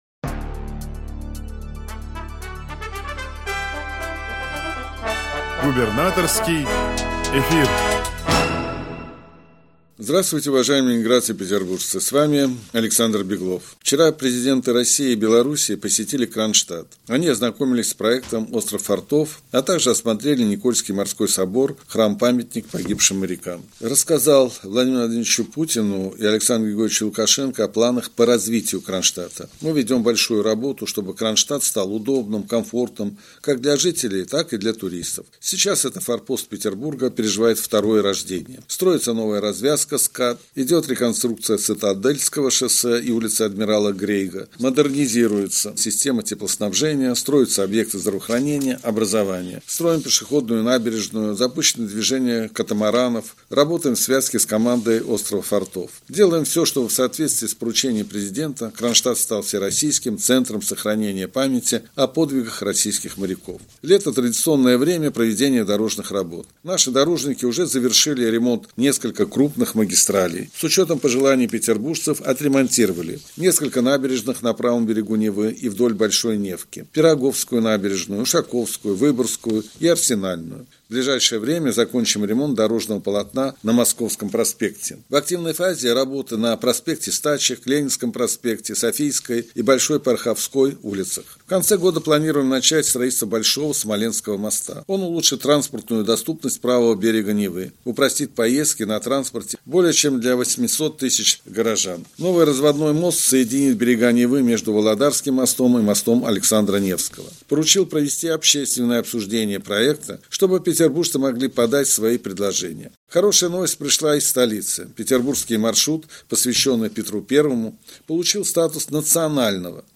Радиообращение 24 июля 2023 года